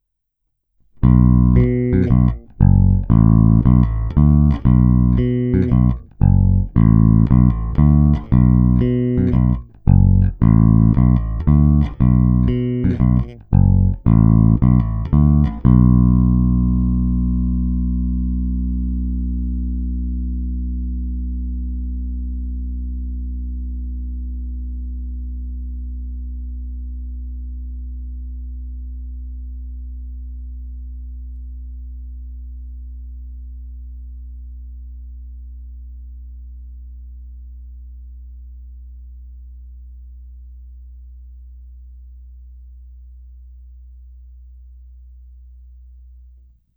Není-li uvedeno jinak, následující nahrávky jsou provedeny rovnou do zvukové karty, jen normalizovány, jinak ponechány bez úprav.
Není-li uvedeno jinak, hráno bylo v pasívním režimu s plně otevřenou tónovou clonou.
Snímač u krku